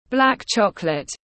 Sô-cô-la đen tiếng anh gọi là black chocolate, phiên âm tiếng anh đọc là /blæk ˈtʃɒk.lət/
Black chocolate /blæk ˈtʃɒk.lət/